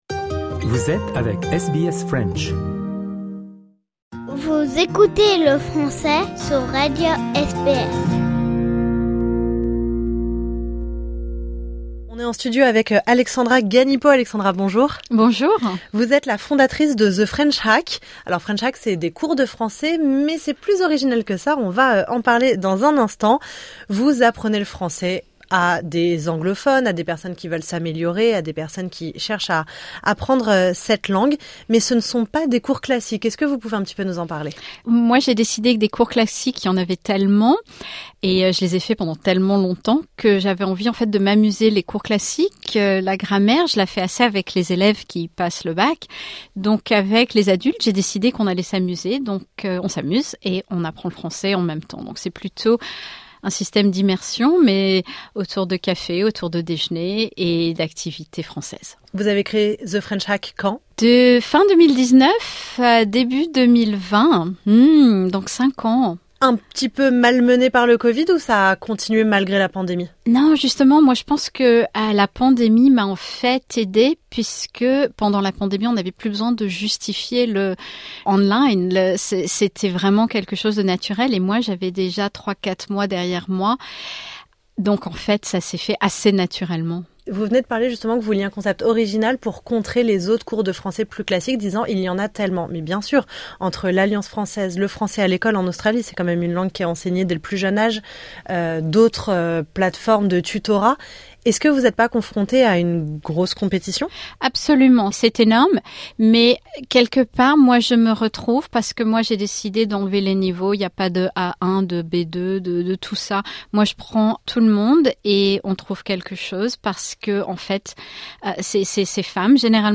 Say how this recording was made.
dans les studios de SBS Melbourne